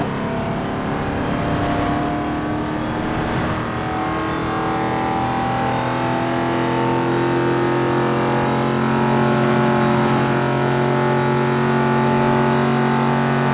Index of /server/sound/vehicles/lwcars/volvo_s60
fourth_cruise.wav